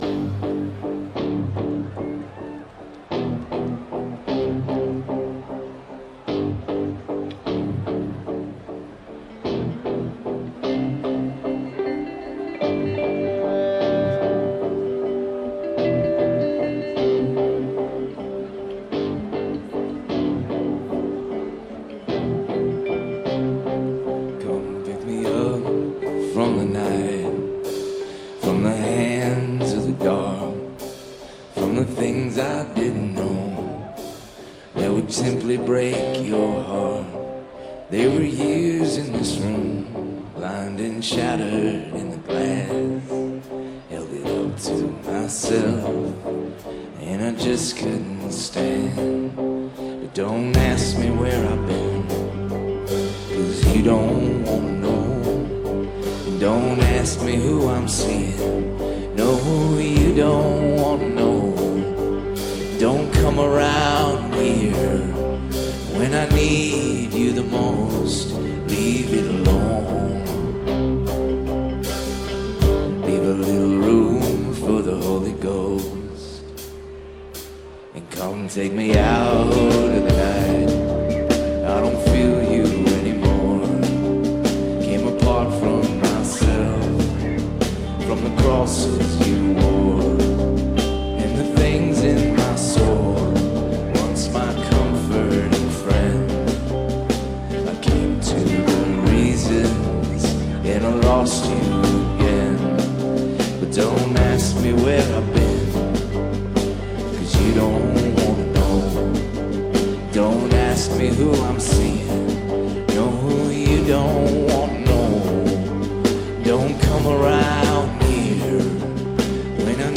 Last performance before their extended hiatus
live at Reading/Leeds Festival 2015